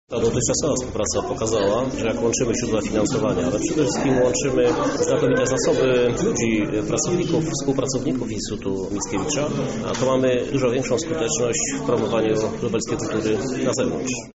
Mówi prezydent miasta – Krzysztof Żuk: